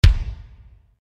Звуки басов